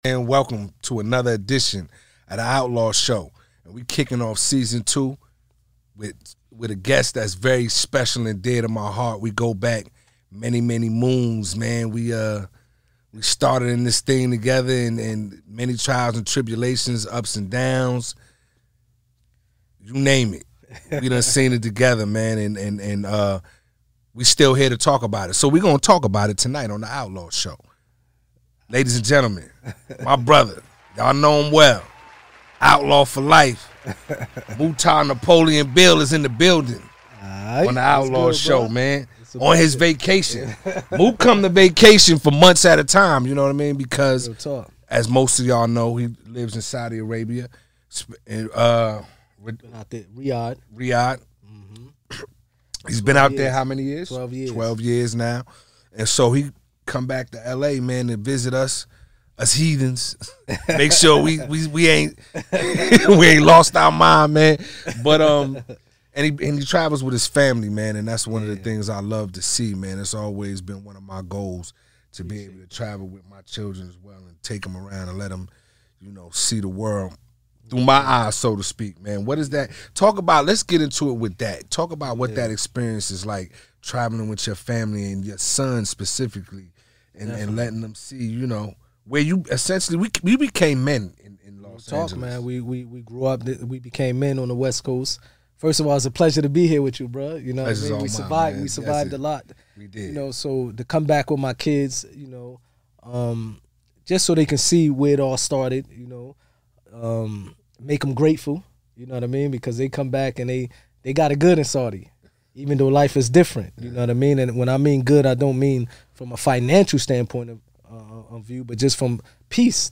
Hosted by E.D.I. Mean